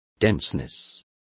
Complete with pronunciation of the translation of denseness.